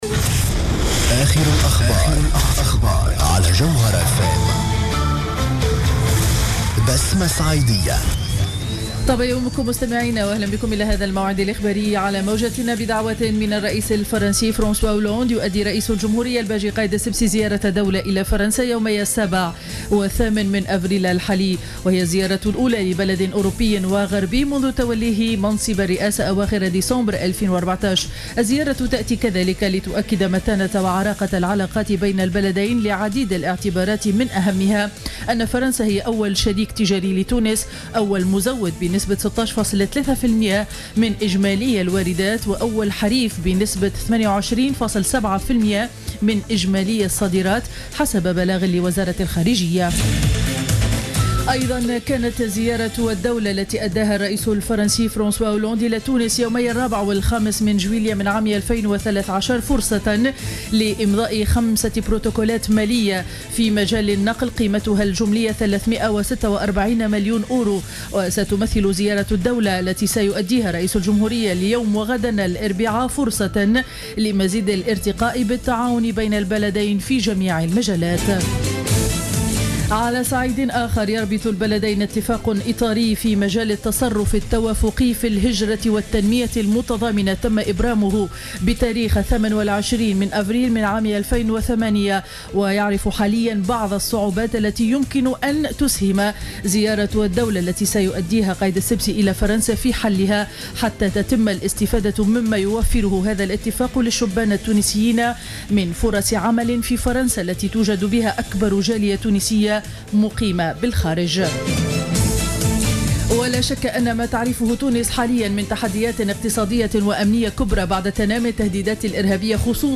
نشرة أخبار السابعة صباحا ليوم الثلاثاء 7 أفريل 2015